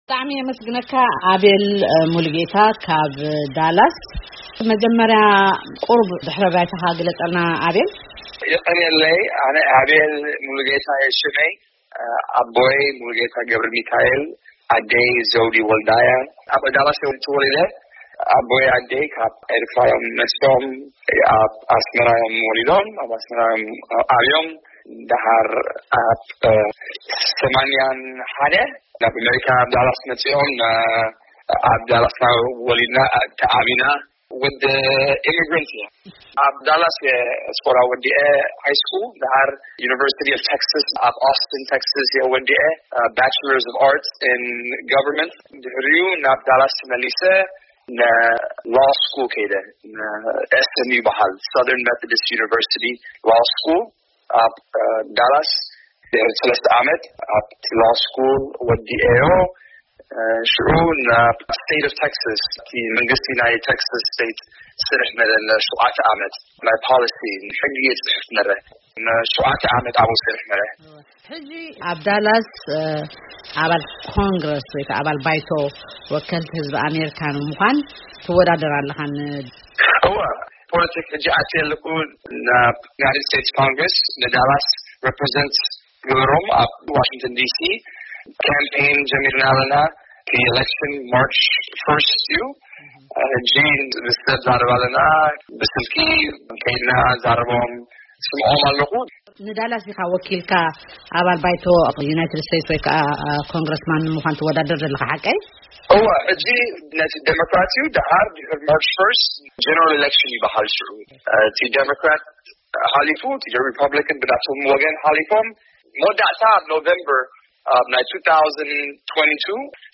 ብዛዕባ መደባቱ ኣዘራሪብናዮ ኣሎና